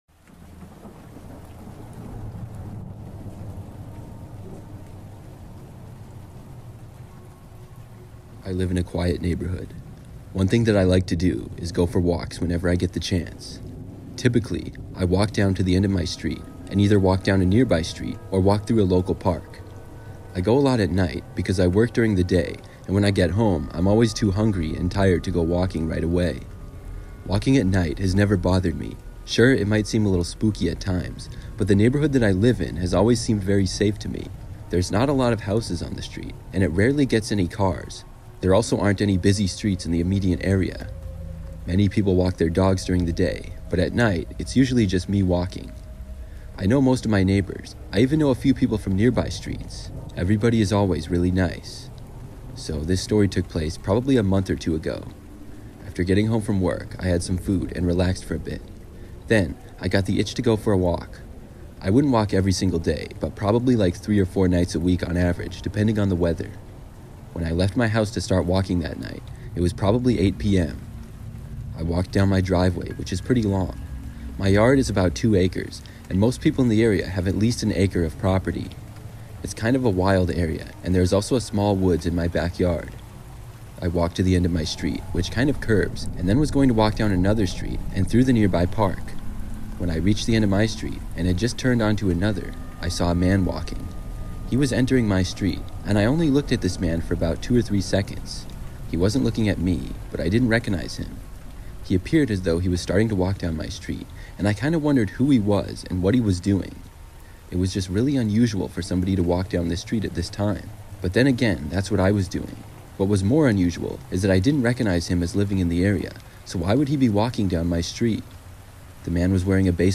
True Hitchhiking Horror Stories (With Rain Sounds) That Will Leave You Terrified